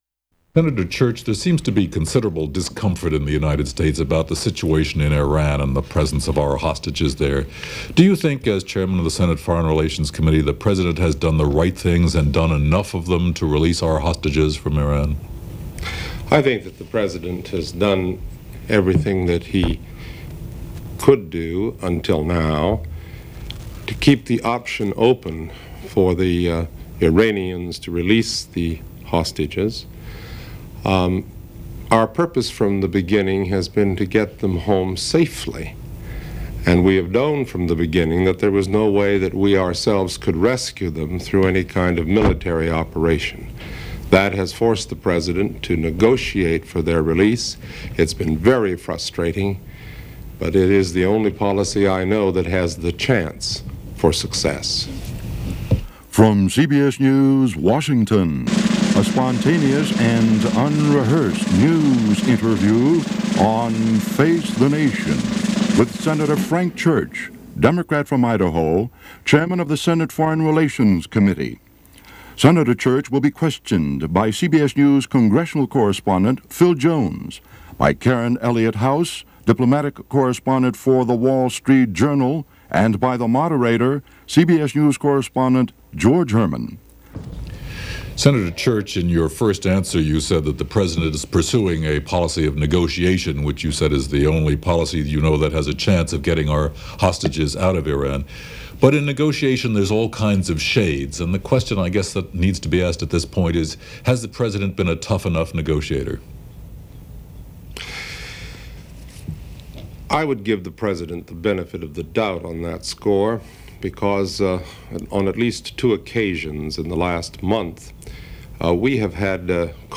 Broadcast on CBS-TV, April 13, 1980.